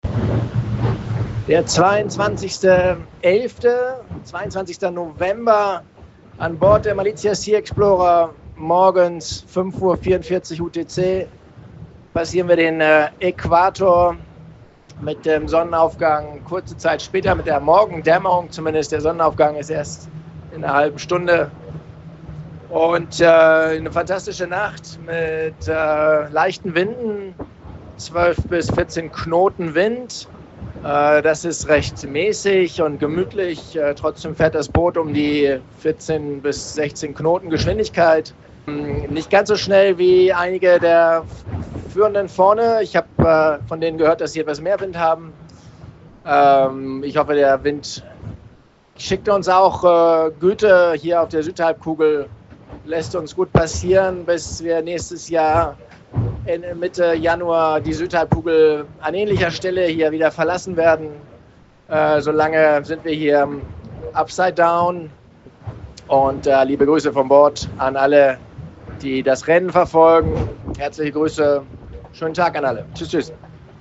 Hier die ganze Botschaft von Bord: